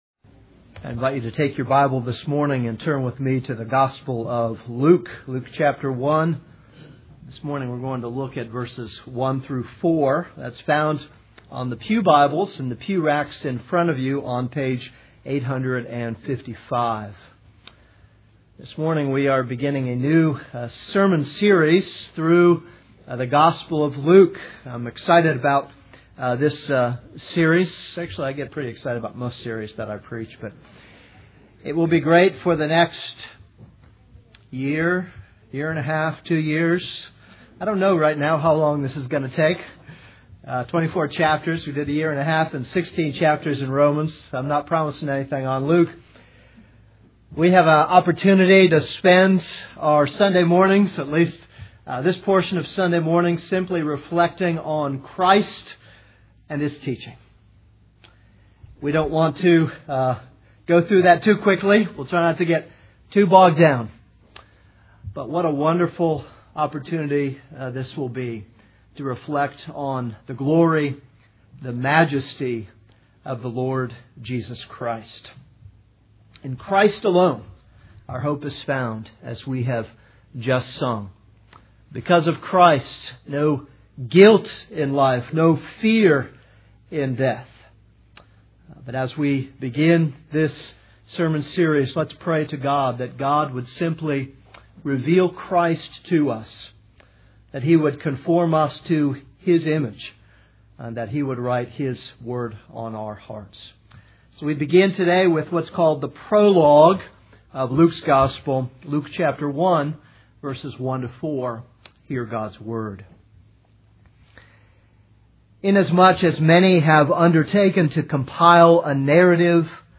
This is a sermon on Luke 1:1-4.